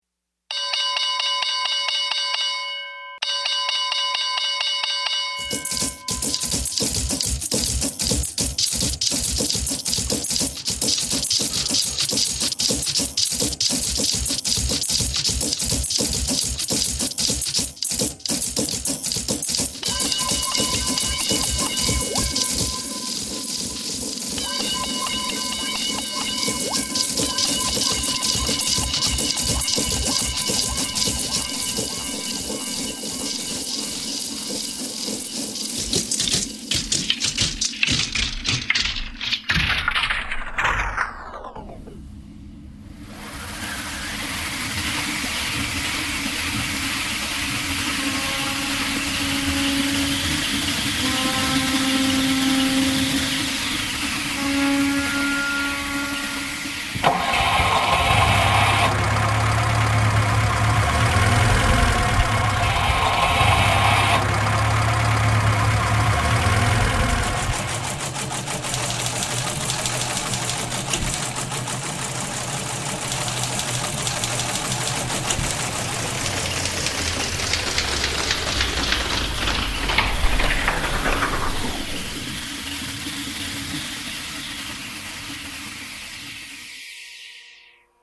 stock ticker